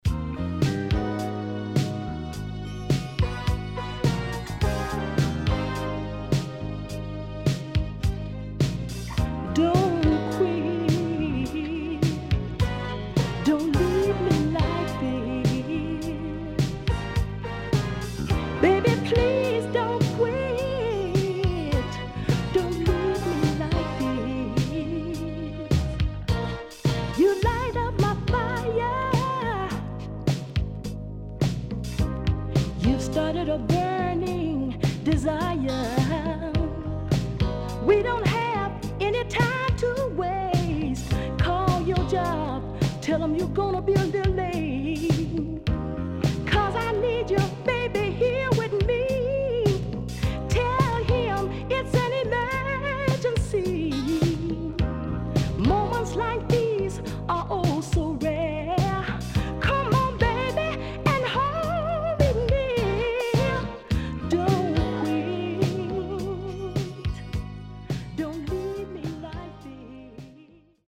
HOME > SOUL / OTHERS
SIDE A:少しチリノイズ入りますが良好です。